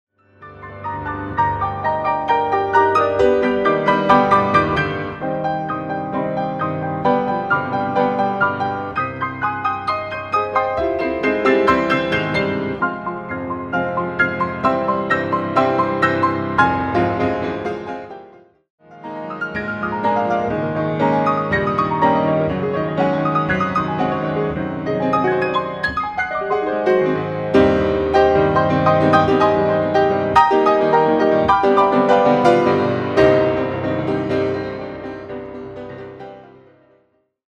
live performance
Difficulty:  Advancedissimo